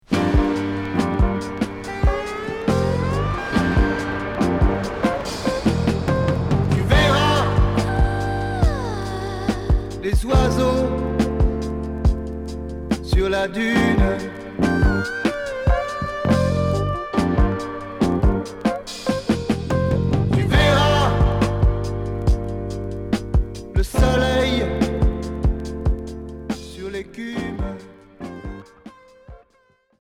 Pop groove Deuxième 45t retour à l'accueil